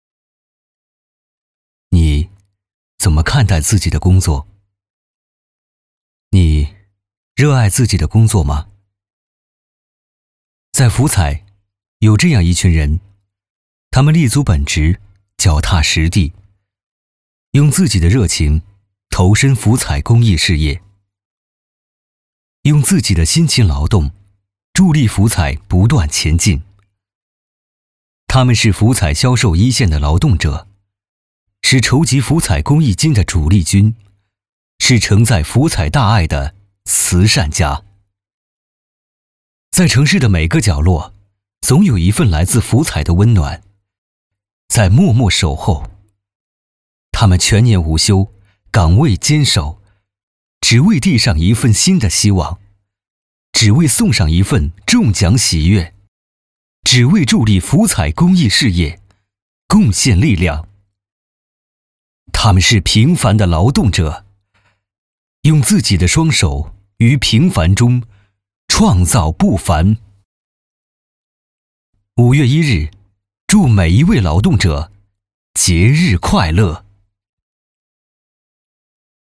男10号